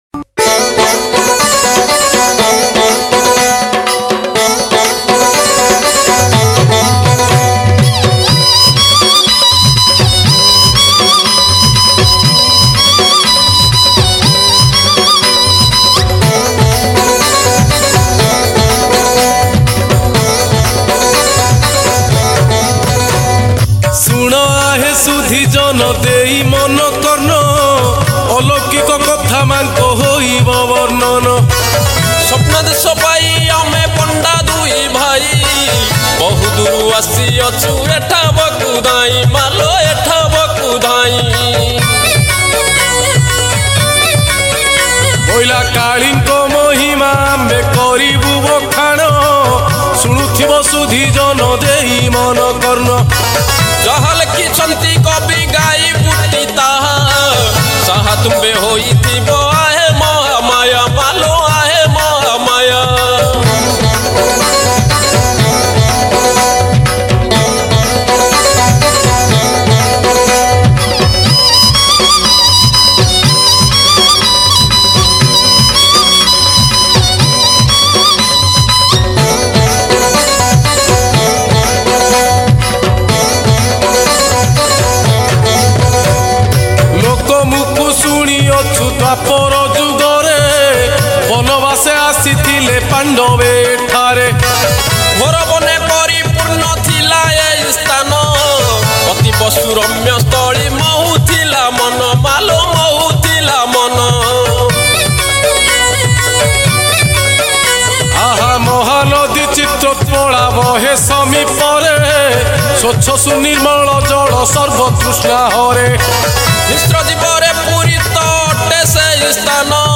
Odia Kali Bhajan